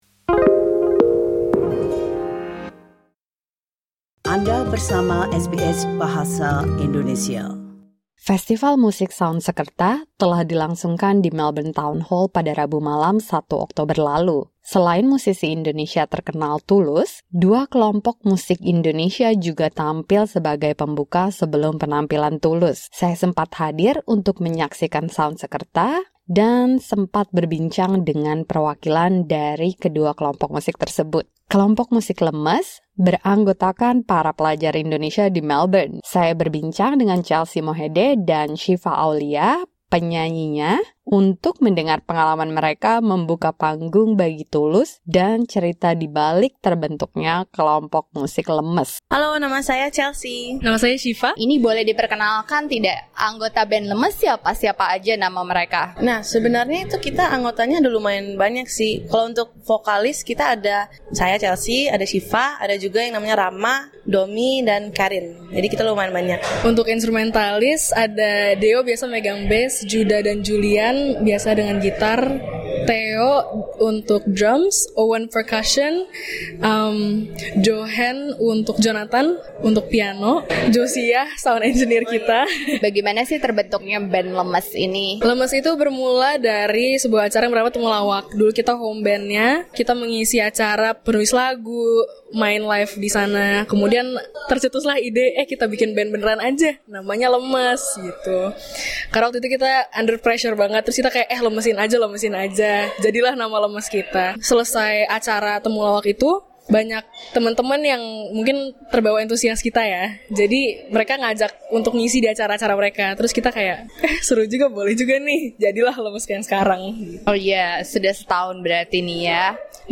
Listen to a conversation on SBS Indonesian